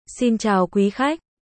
1. Âm thanh lời chào tiếng việt